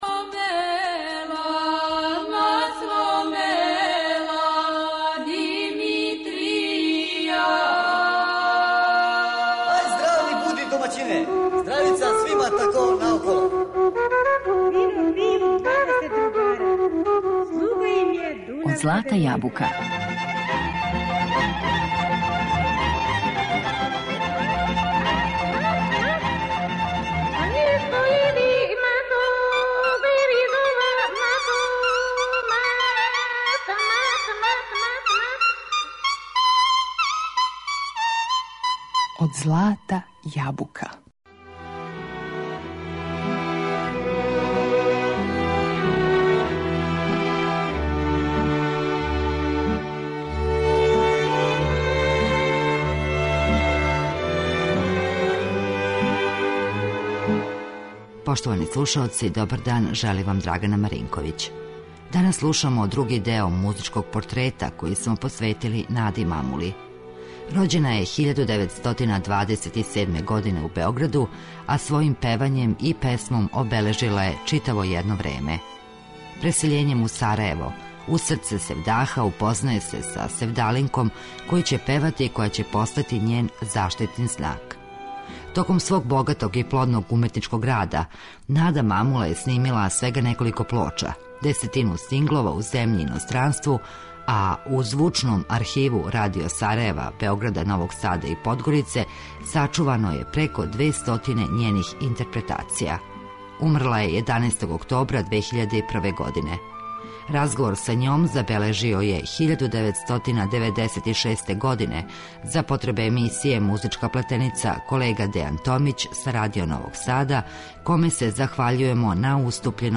Песмом је обележила читаво једно време и својом недостижном интерпретацијом будила најлепша осећања код многих поклоника добре народне песме.